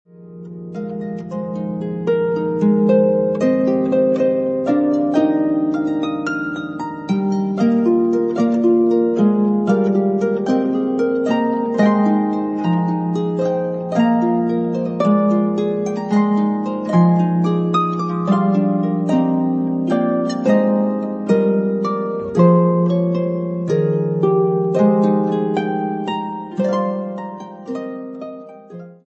beautiful harp music
Traditional Irish